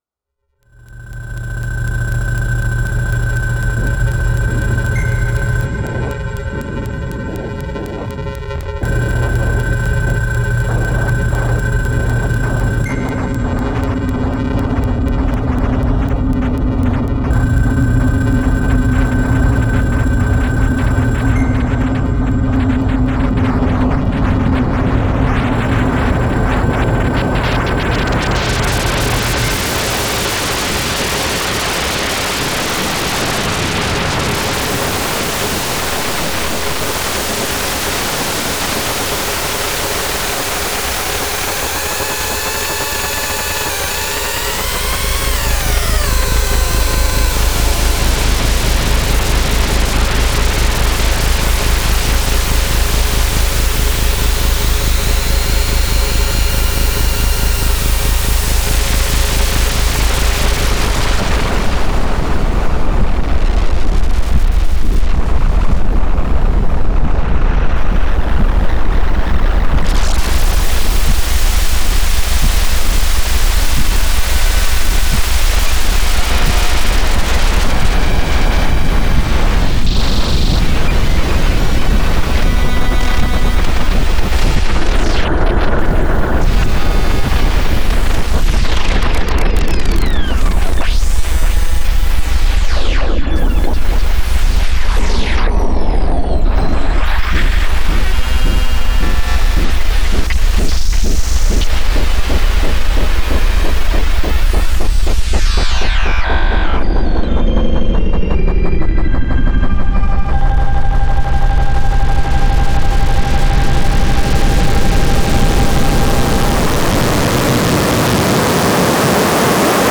A soundscape composition inspired by industrial noise.